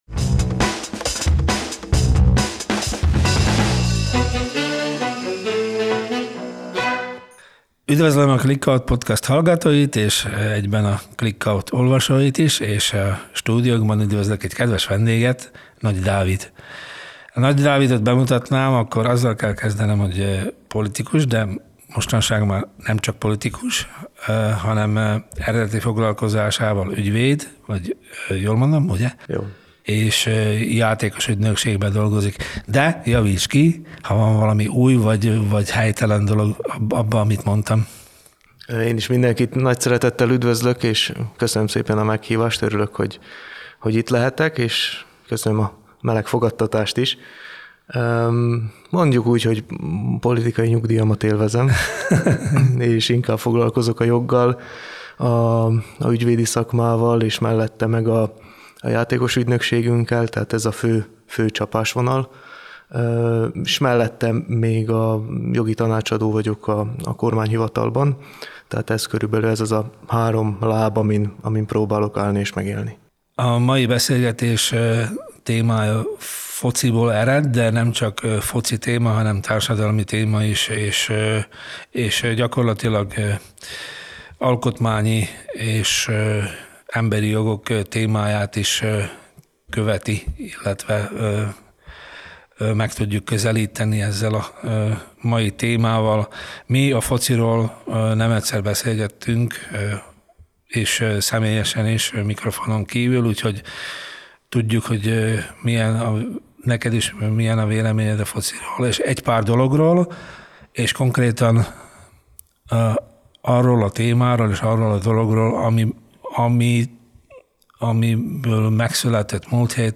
Interjú